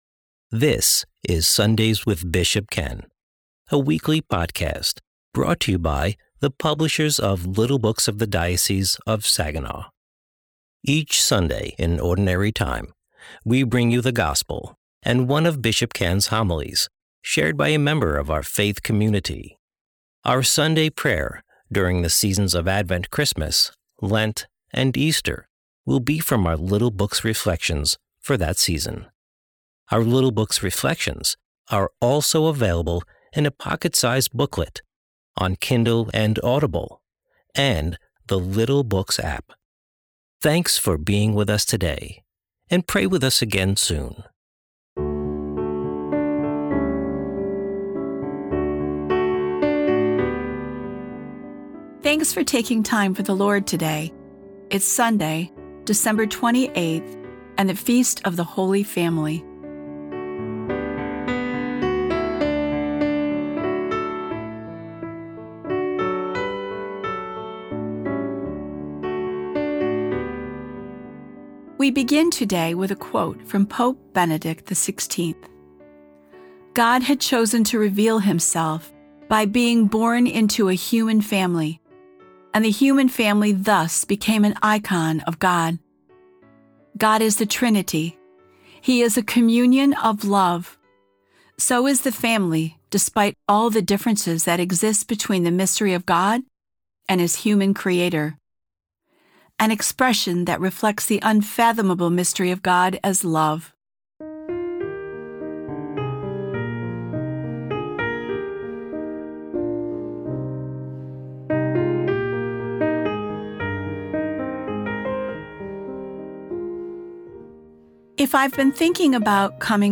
Today's episode of Sunday's with Bishop Ken is a reading from The Little Blue Book: Advent and Christmas 2025. Join us as we continue to ponder the concept of "coming home."